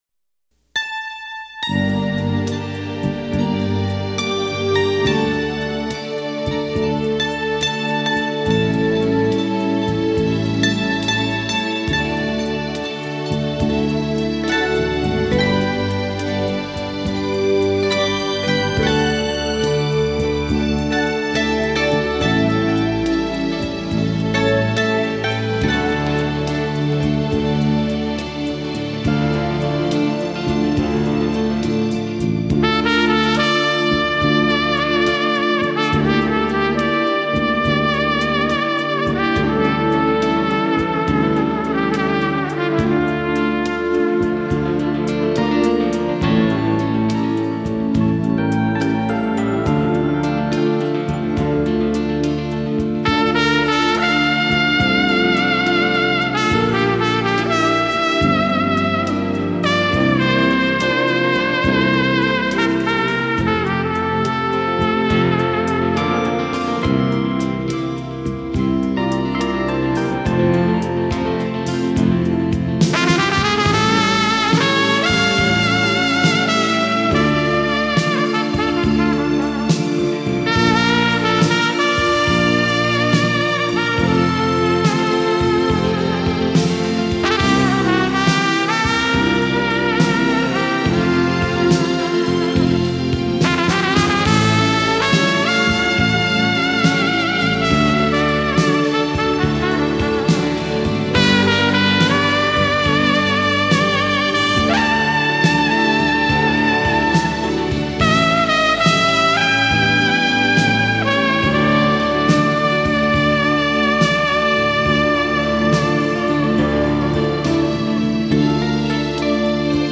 清新脱俗的音符营造出幽静空灵的美感 纯粹、唯美的风格恣意流淌